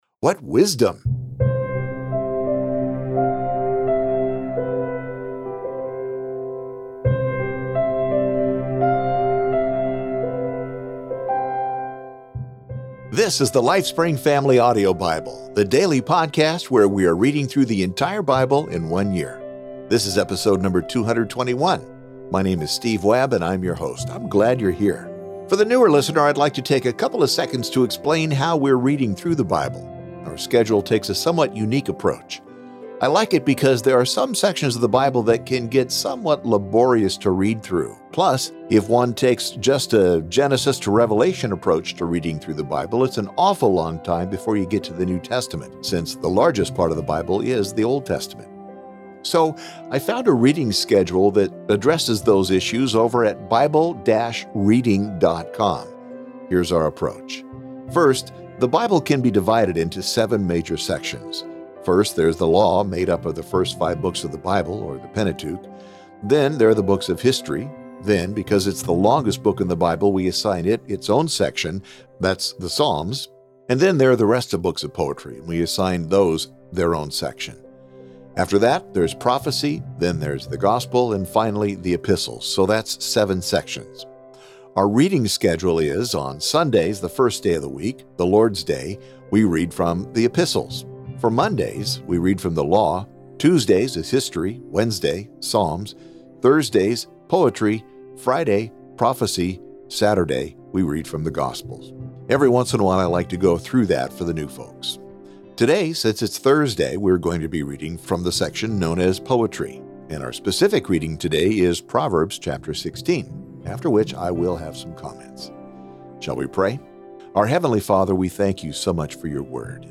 bible reading